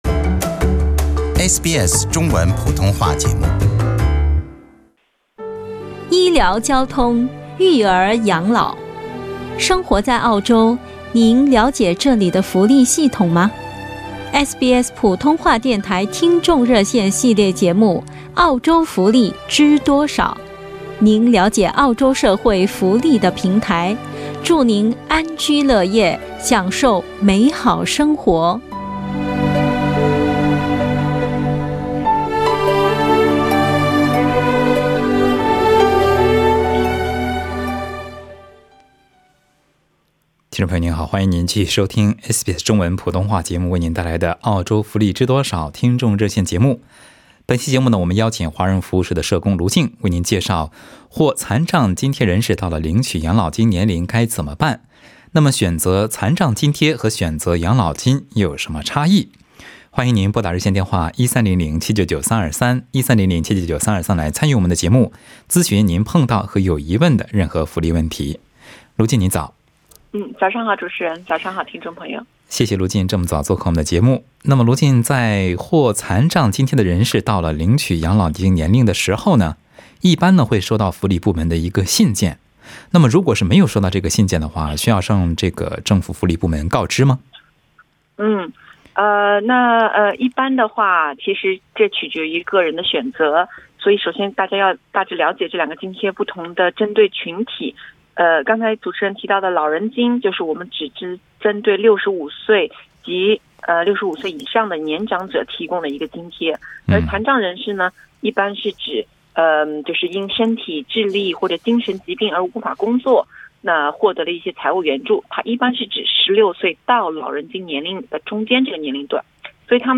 节目中，有听众咨询了照顾海外家庭成员能否获得帮助，退休后工作有无税务优惠，孩子读大学搬出去住有何补助，来澳多久能领福利卡等问题。